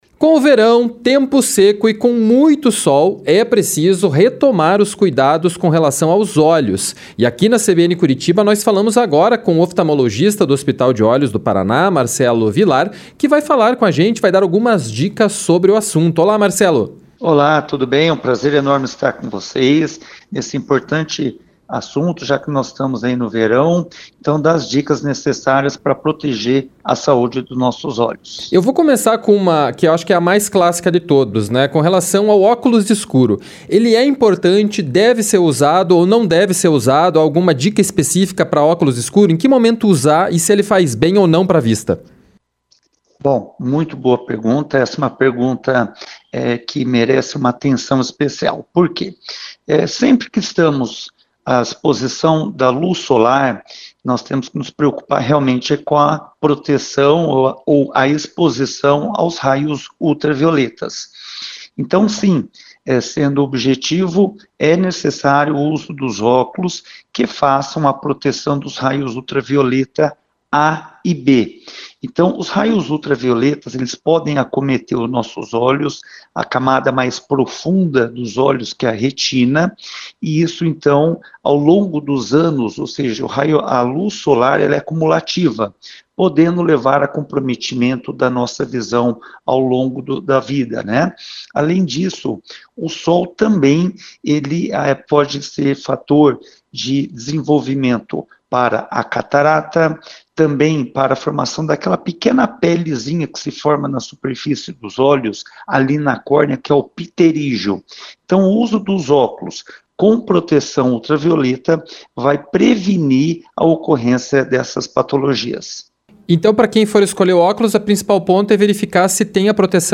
ENTREVISTA-CUIDADO-COM-OS-OLHOS-VERAO.mp3